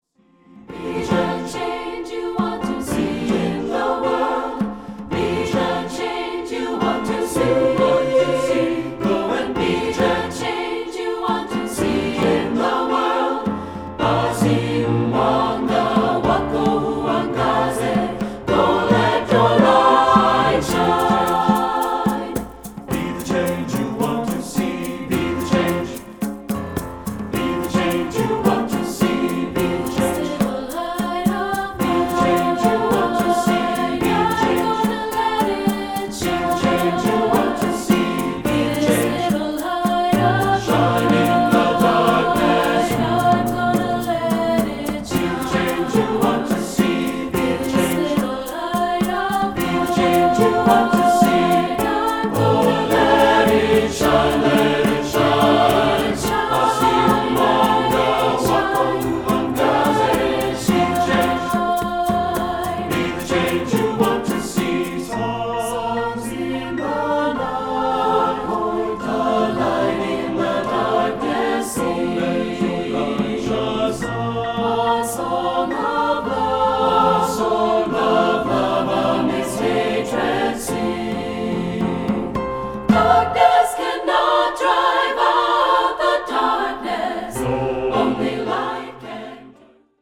Choral Concert/General Graduation/Inspirational
SATB